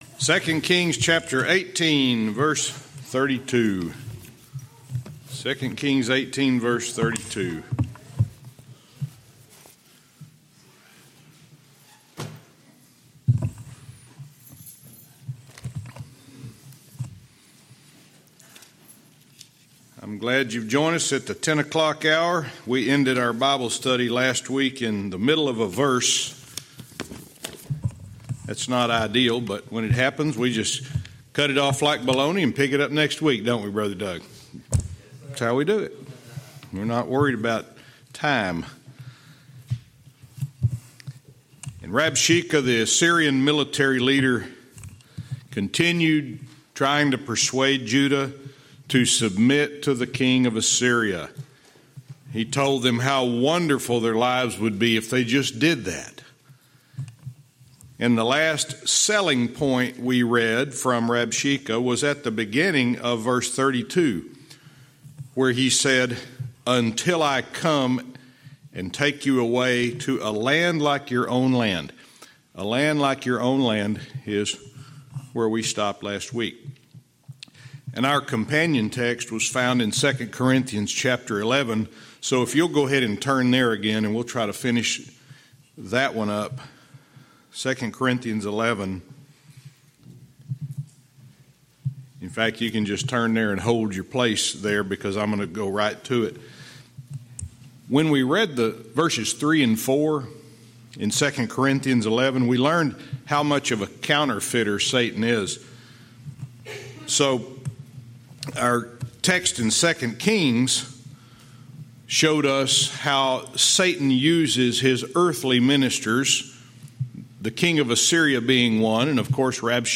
Verse by verse teaching - 2 Kings 18:32 Part 2